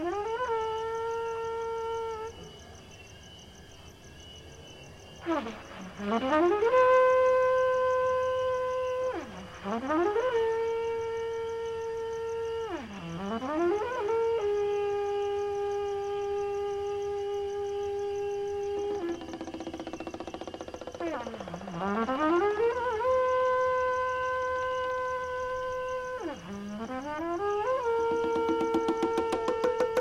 The 50 Best Ambient Albums of All Time